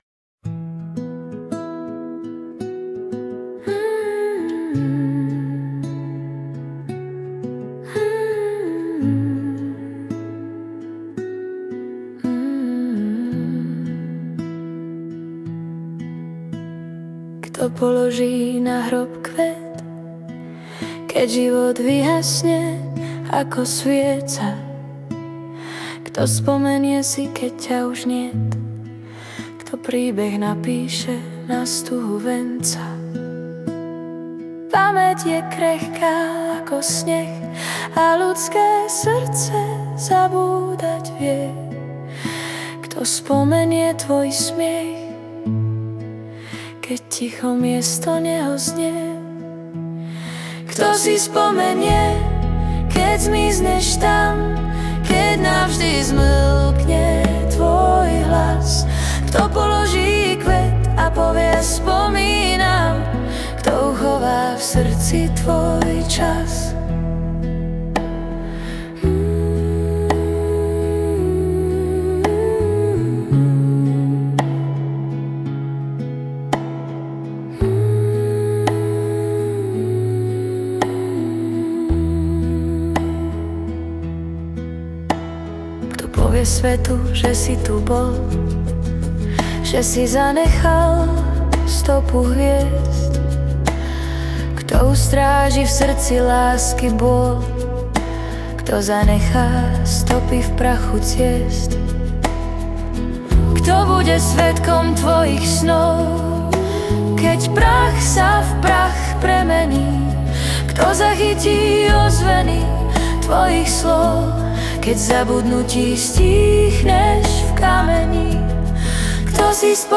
Hudba a spev AI
Balady, romance » Smutné